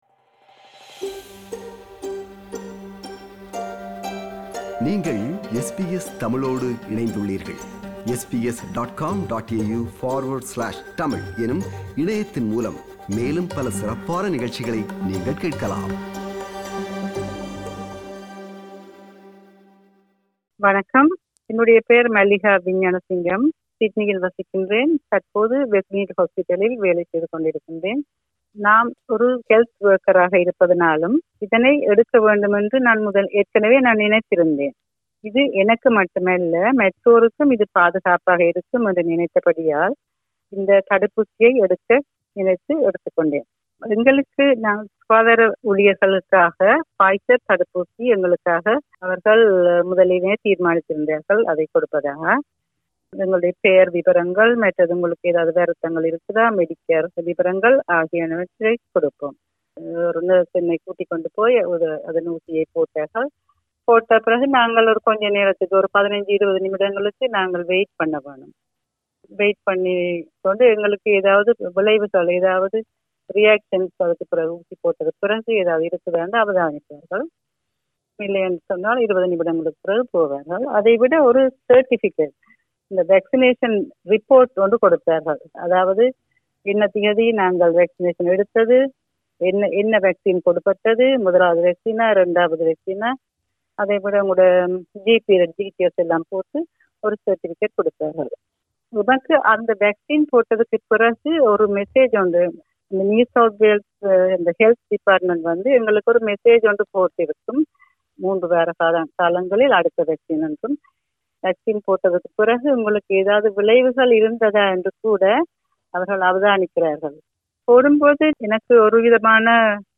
Few of our listeners are sharing their experience and reactions after they had their first dose of the COVID-19 vaccine.